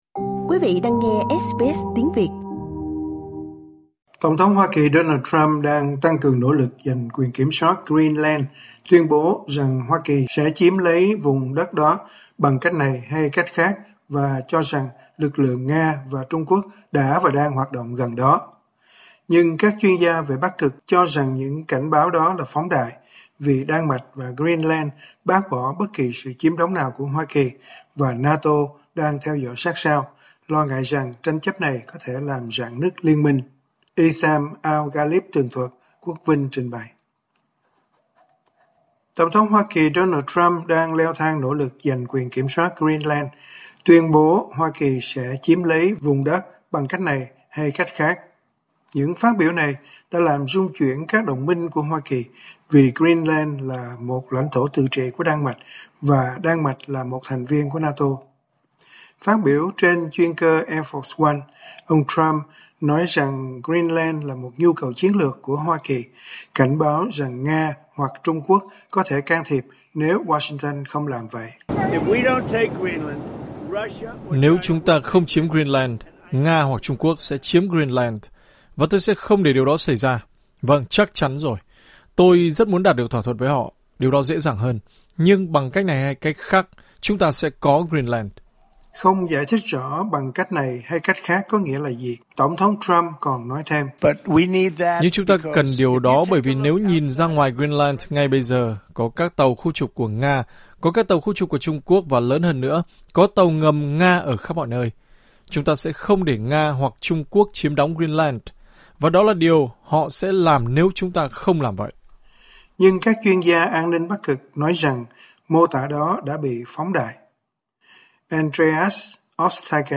TT Trump trả lời báo chí trên chuyên cơ AF One